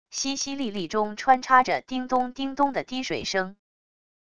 淅淅沥沥中穿插着叮咚叮咚的滴水声wav音频